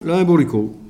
Localisation Saint-Hilaire-des-Loges
Catégorie Locution